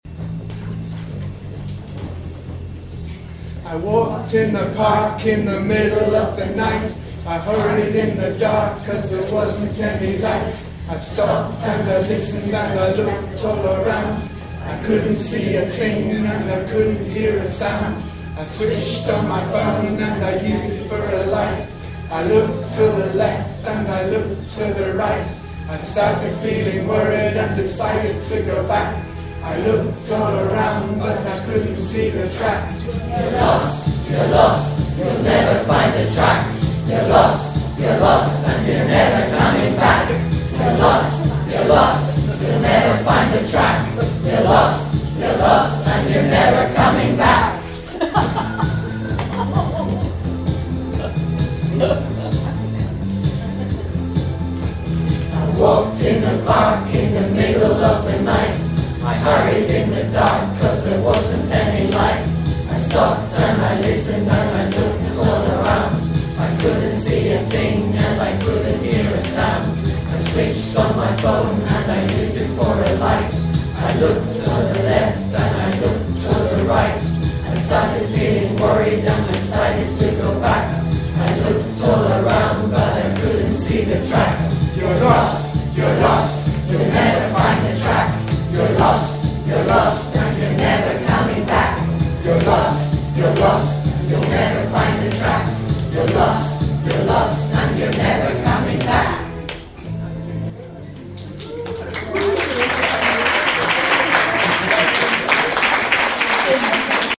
Lost at TESOL Spain.mp3